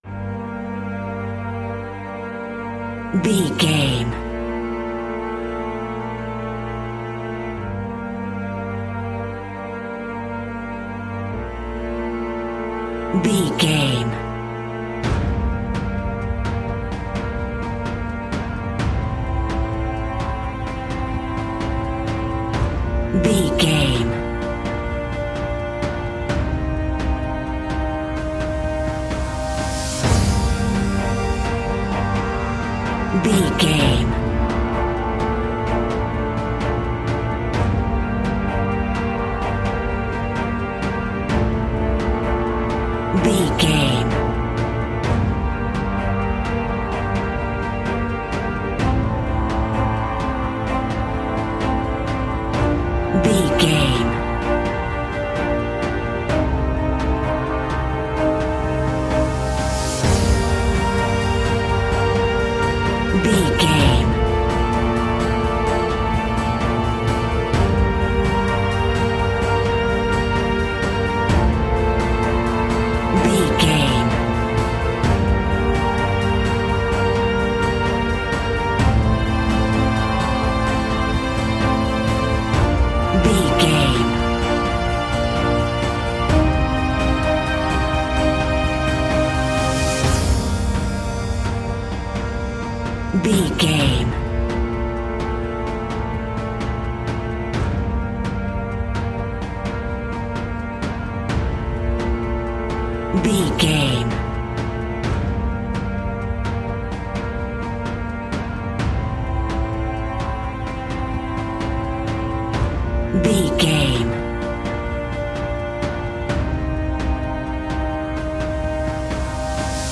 Aeolian/Minor
dramatic
epic
percussion
synthesiser
brass
violin
cello
double bass